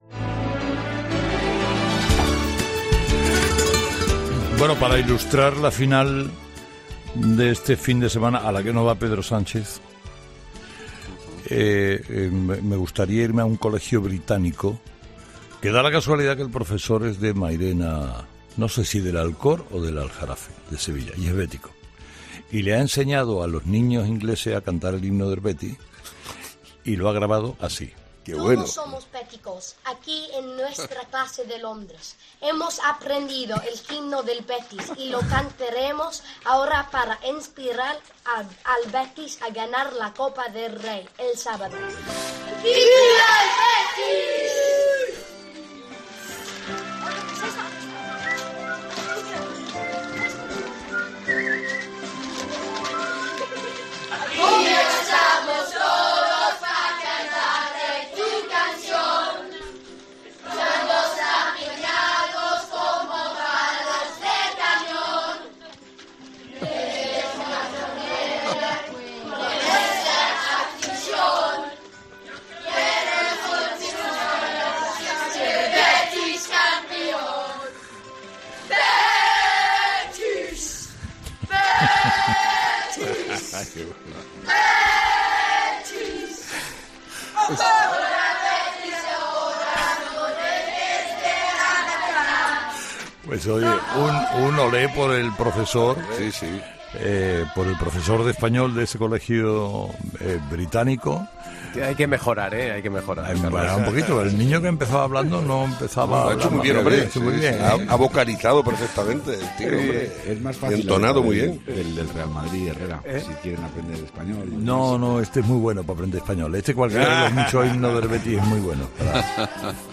La reacción de Herrera cuando escucha a una clase de niños británicos cantar el himno del Betis: "¡Un olé!"
El comunicador y presentador de 'Herrera en COPE' compartía el momento en el que unos alumnos ingleses se atrevían con el himno del equipo sevillano